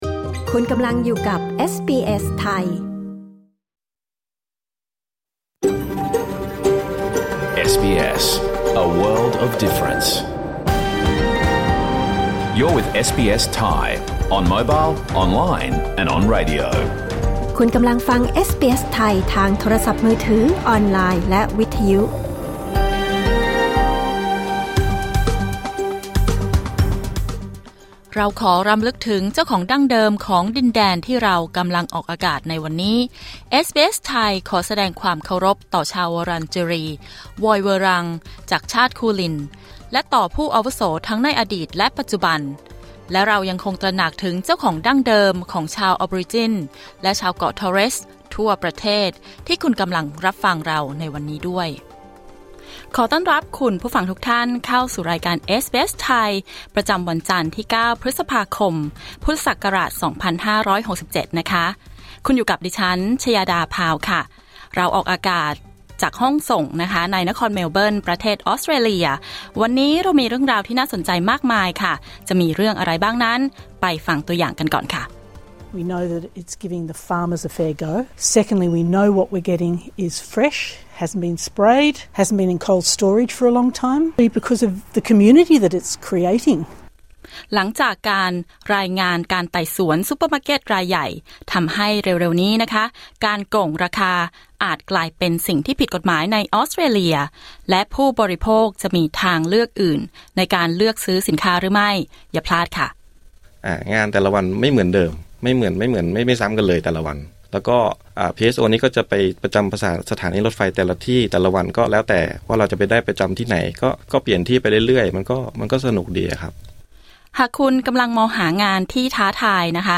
รายการสด 9 พฤษภาคม 2567